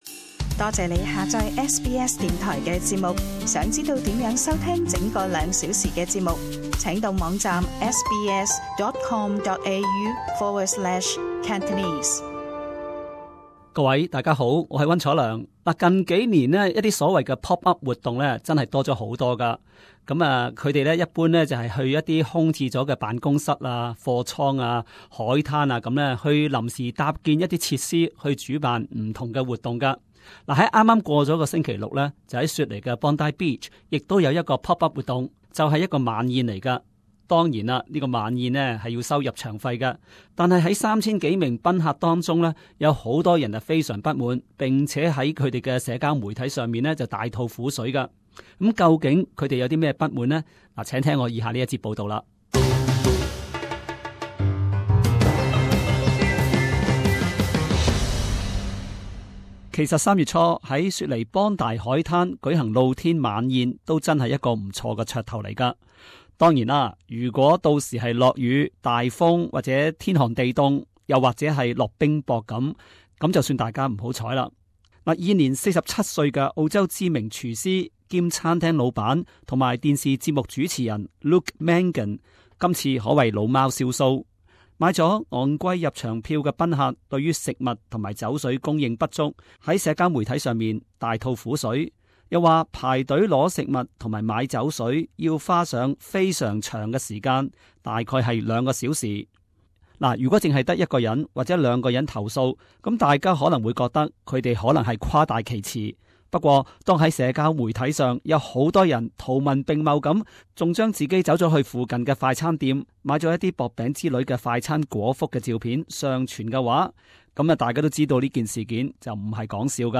【时事报导】 Bondi 海滩 Pop Up 晚宴被人投诉货不对办